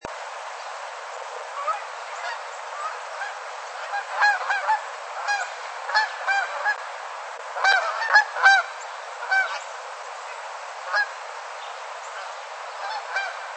Canada Goose
The goose has a deep, distinctive honk.
canadian-goose-call.mp3